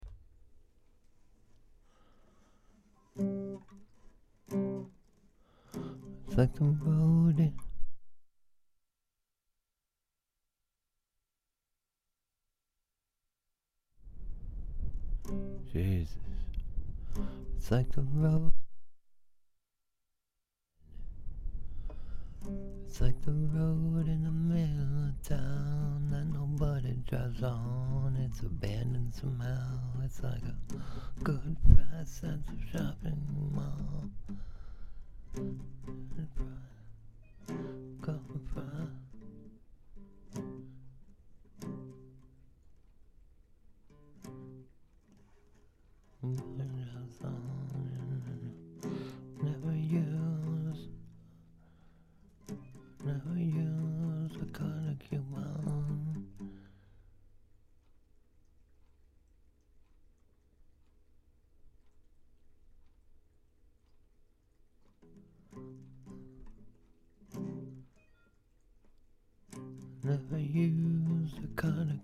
Mic Cutting out On ableton live 9.7
anyway im using an MXL V57M condenser . im attaching a clip thats cutting out .